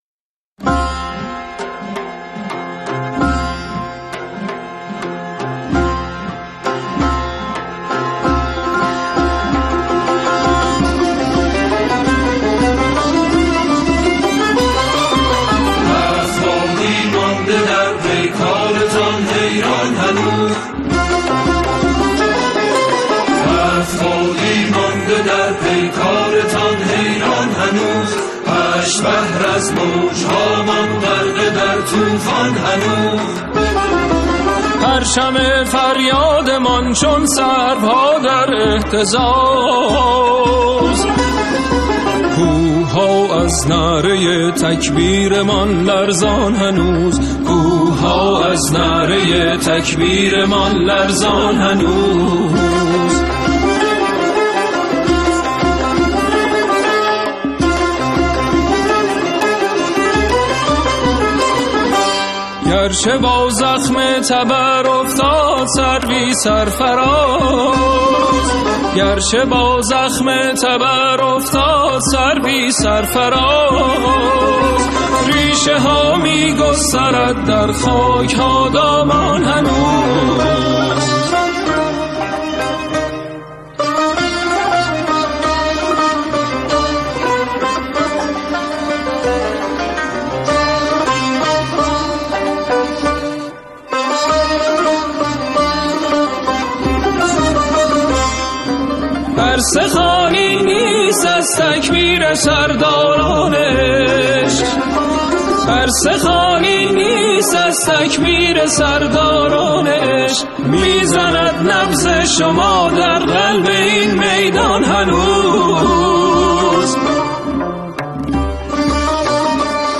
گروهی از همخوانان
آنها در این قطعه، شعری را درباره شهدا همخوانی می‌کنند.